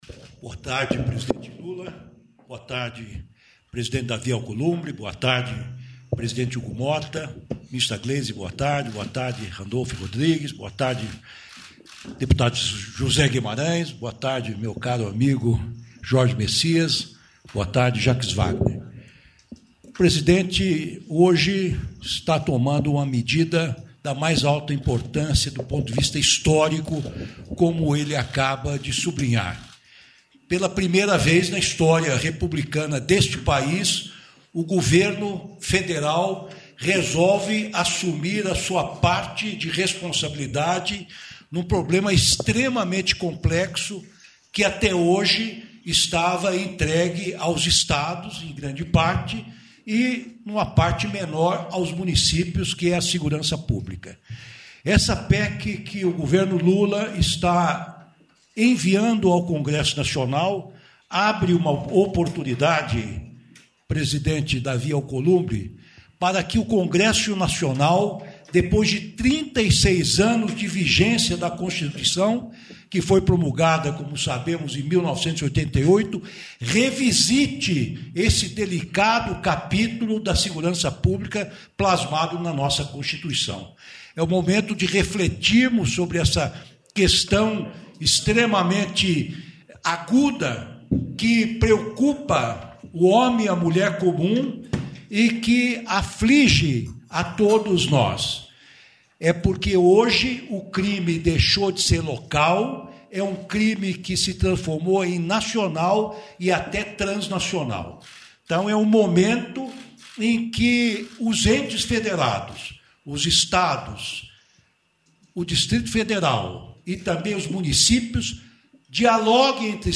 Íntegra da entrevista concedida pelo ministro da Justiça e Segurança Pública, Ricardo Lewandowski, e pelo secretário Nacional de Políticas Penais, André Garcia, após apresentação do balanço das ações implementadas após fuga que ocorreu em 2024, em Mossoró (RN).